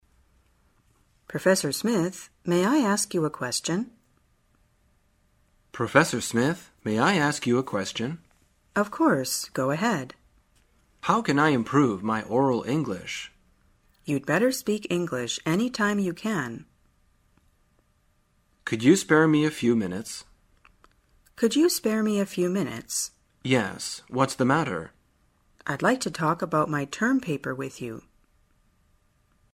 真人发音配字幕帮助英语爱好者们练习听力并进行口语跟读。